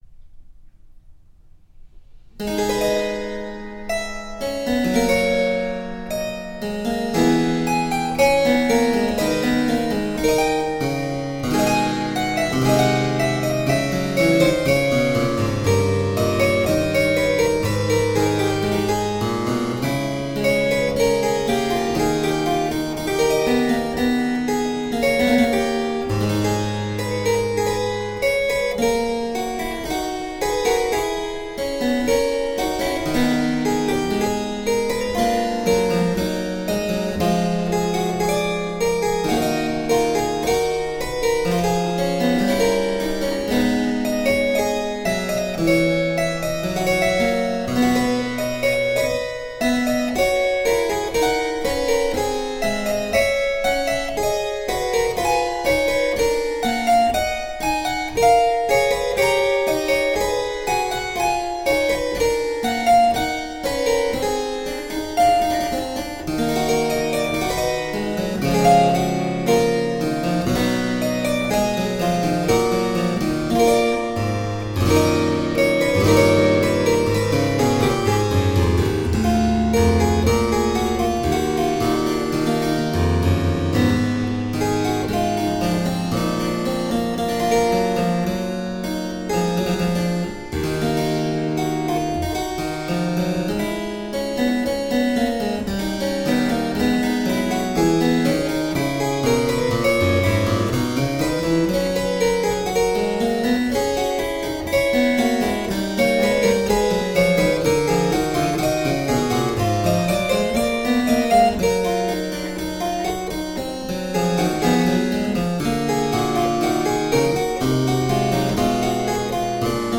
three different beautiful harpsichords
Classical, Baroque, Instrumental, Harpsichord